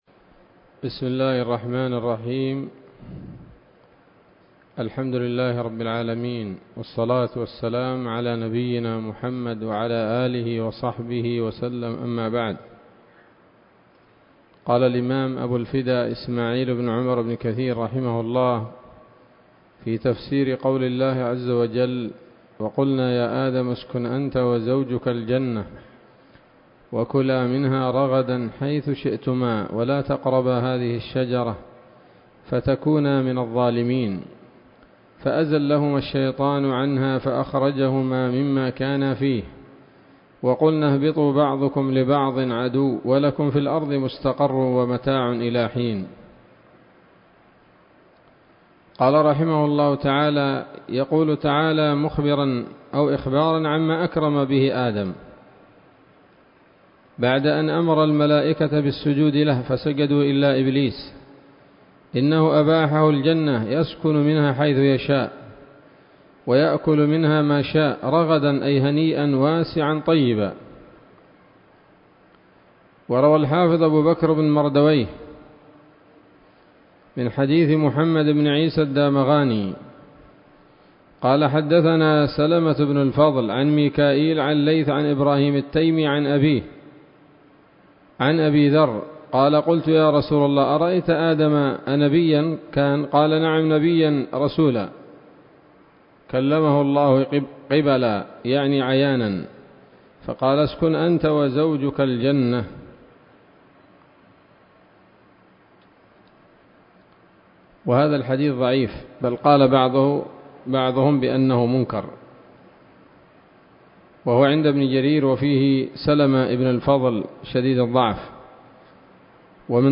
الدرس الأربعون من سورة البقرة من تفسير ابن كثير رحمه الله تعالى